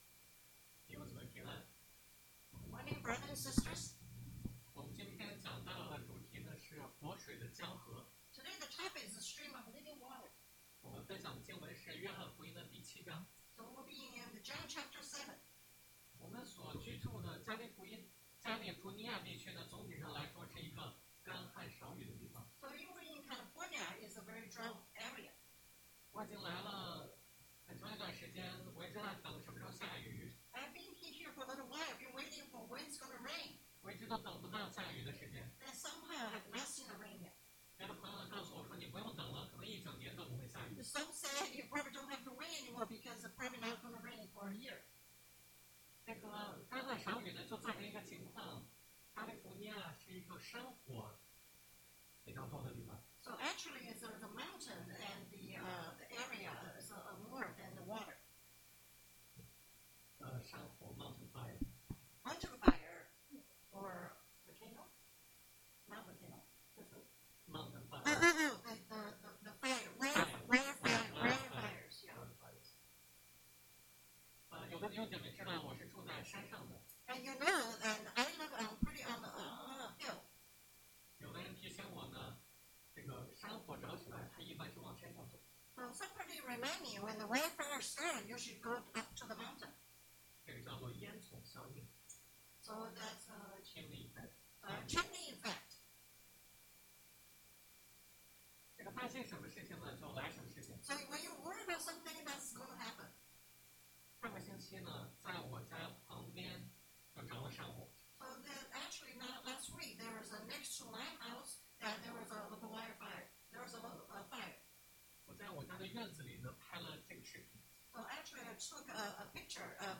Passage: 约翰福音 John Chapter 7 Service Type: Sunday AM